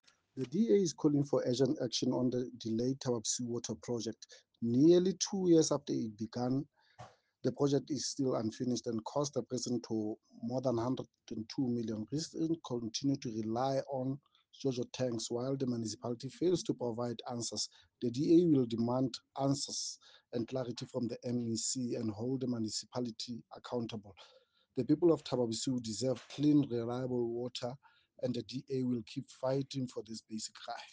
Sesotho soundbites by Cllr Paseka Mokoena and